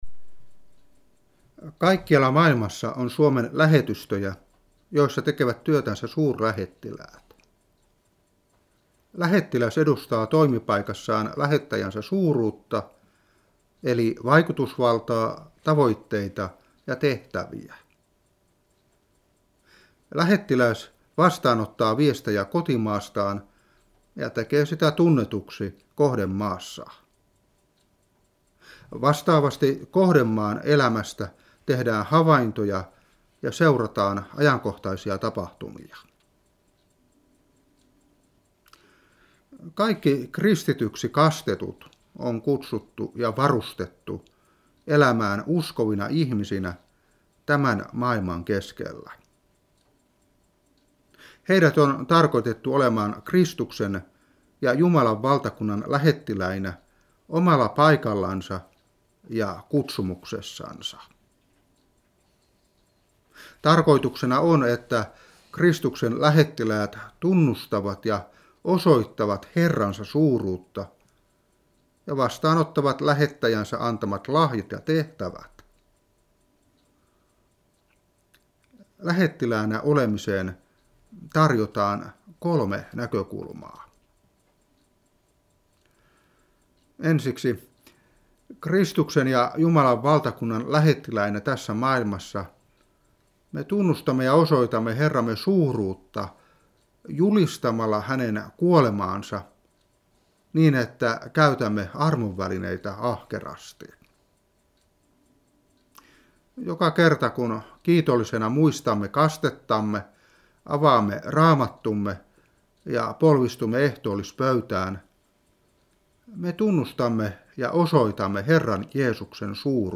Saarna 2012-10.